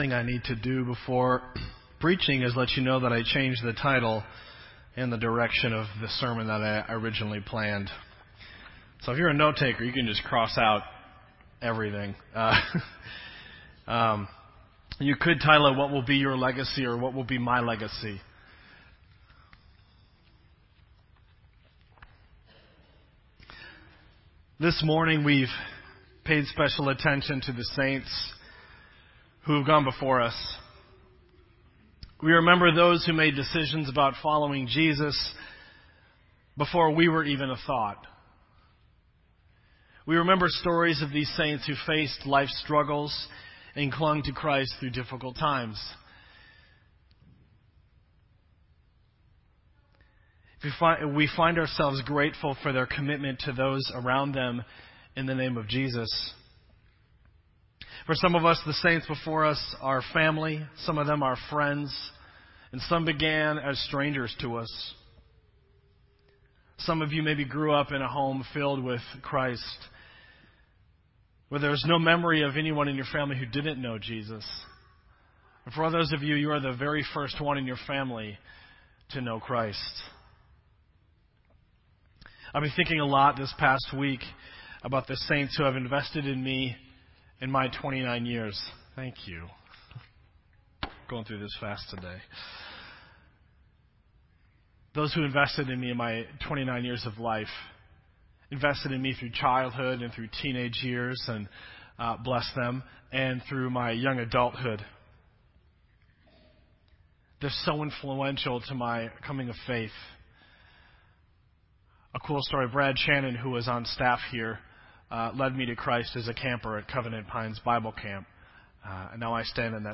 Worship Service
Sermon Audio